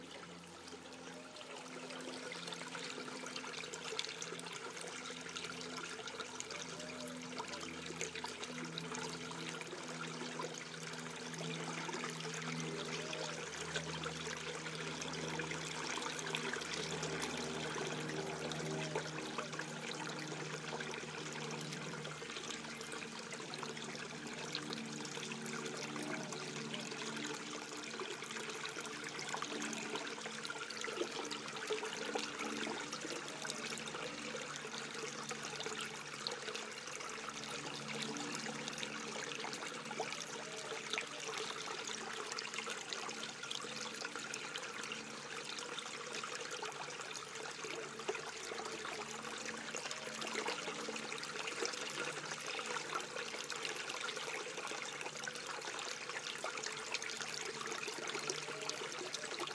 60 seconds of: Guiseley wells ambience